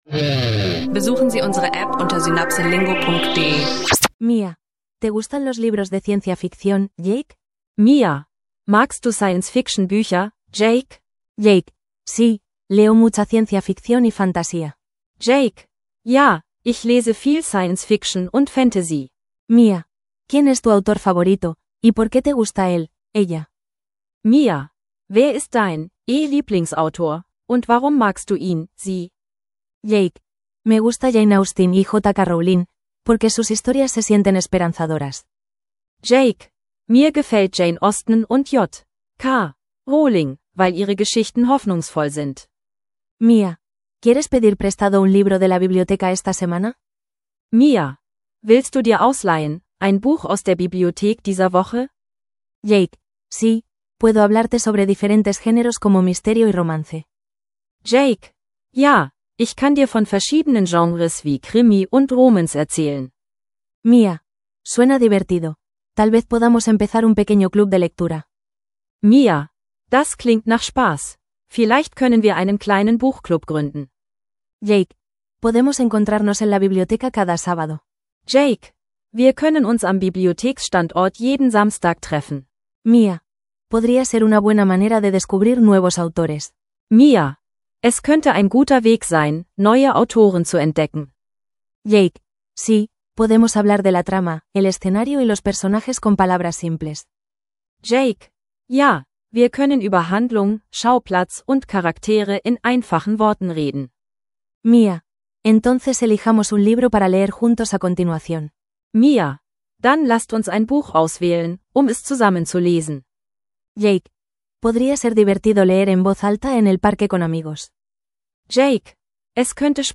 Kurze Dialoge über Bücher und Genres – lerne Spanisch im Alltag mit spannenden Gesprächen über Literatur